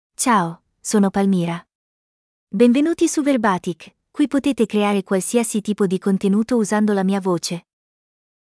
Palmira — Female Italian (Italy) AI Voice | TTS, Voice Cloning & Video | Verbatik AI
Palmira is a female AI voice for Italian (Italy).
Voice sample
Listen to Palmira's female Italian voice.
Female
Palmira delivers clear pronunciation with authentic Italy Italian intonation, making your content sound professionally produced.